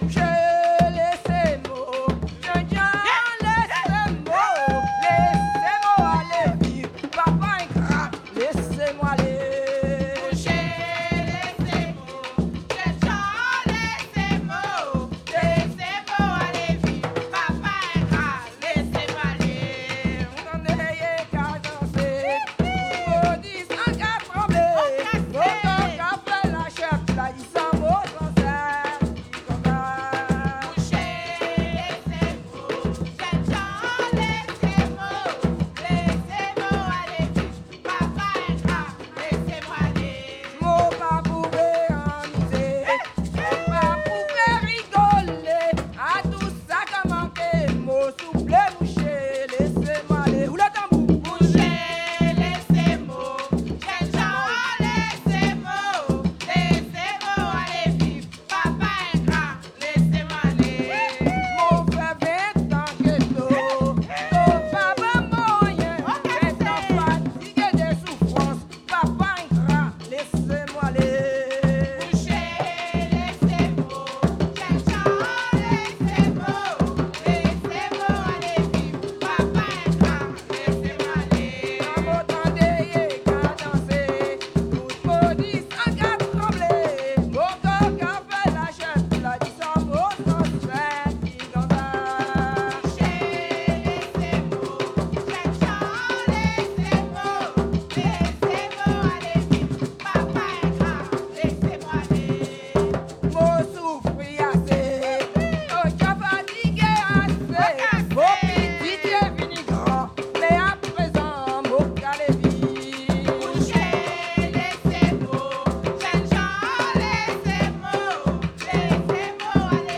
Soirée Mémorial
Saint-Georges-de-l'Oyapoc
danse : kasékò (créole)
Pièce musicale inédite